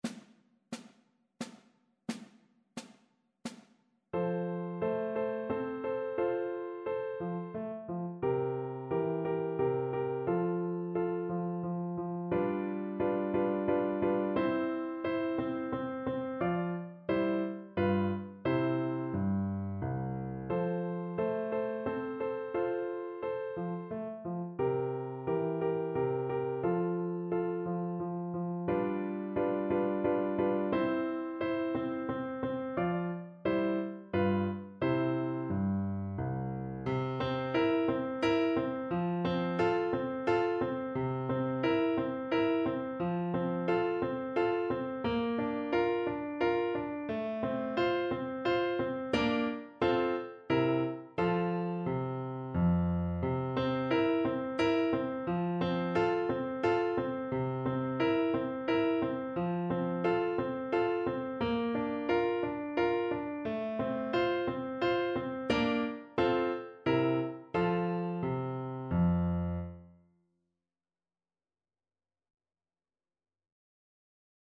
Mozart: Menuet z opery Don Giovanni (na klarnet i fortepian)
Symulacja akompaniamentu